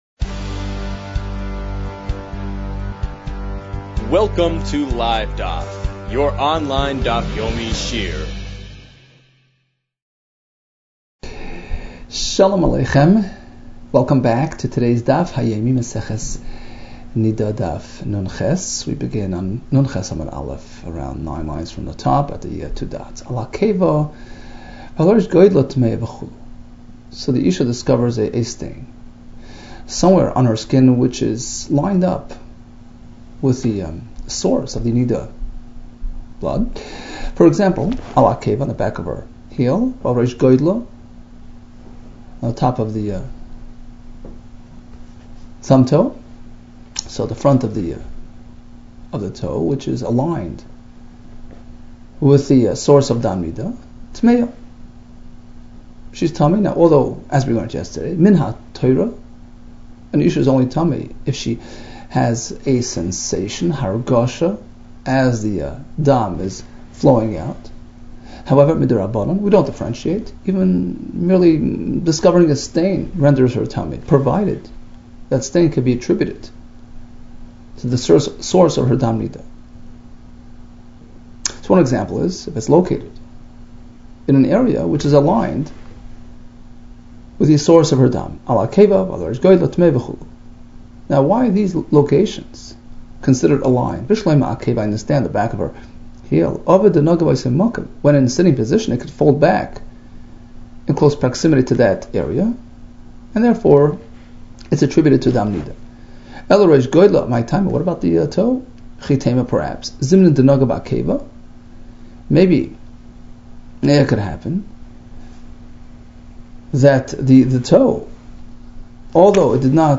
Niddah 58 - נדה נח | Daf Yomi Online Shiur | Livedaf